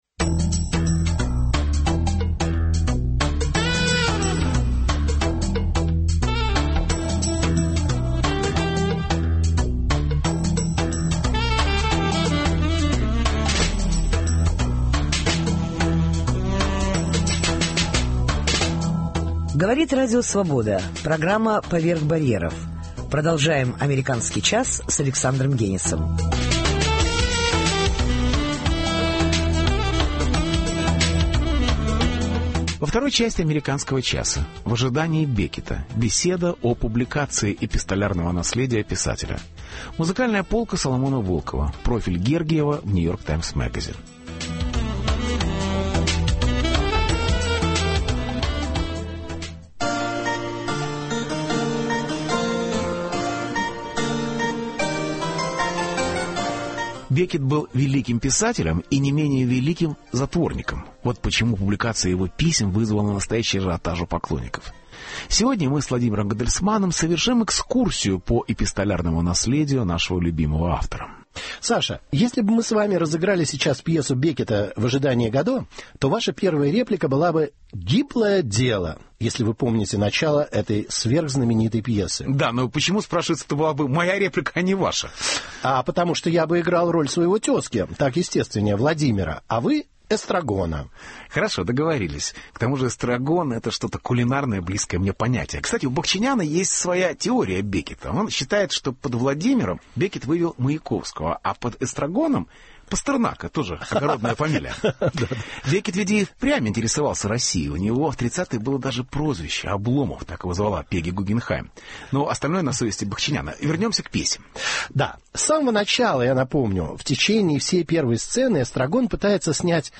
В ожидании Беккета -беседа о публикации эпистолярного наследия писателя.